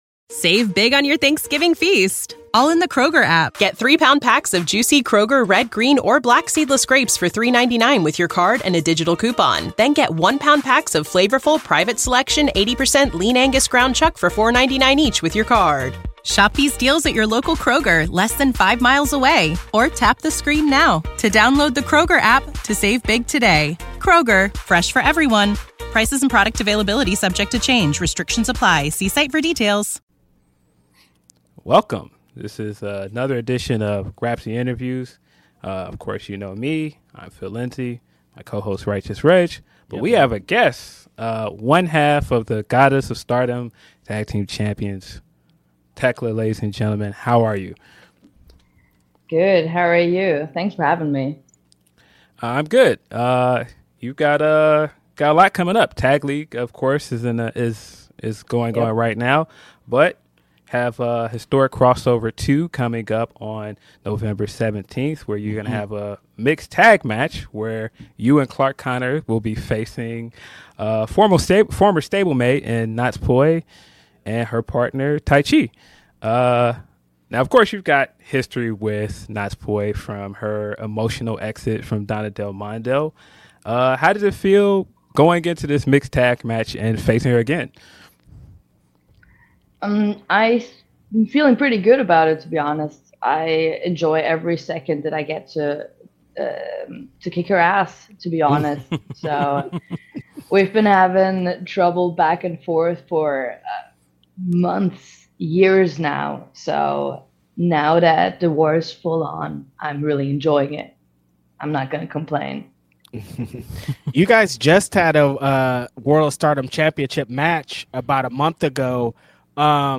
Grapsody Interviews | Fightful News
Shoot Interviews Nov 15